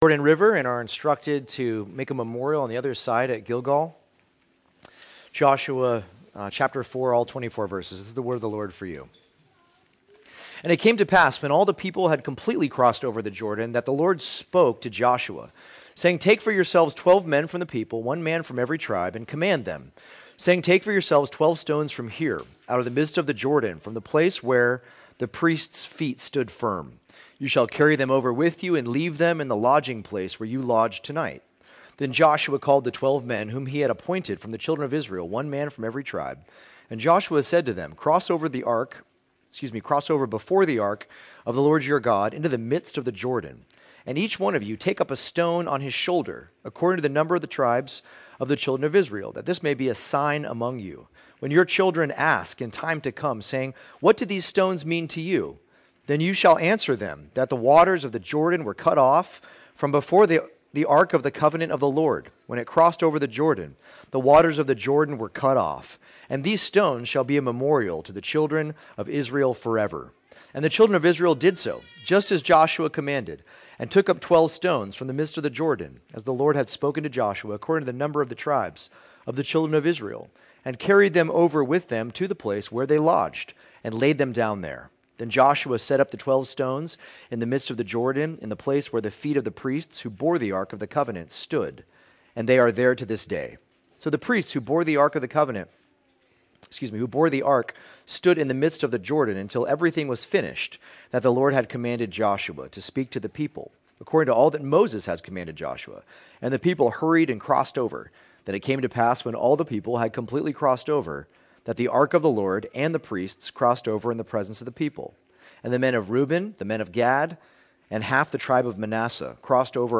2024 Stones of Remembrance Preacher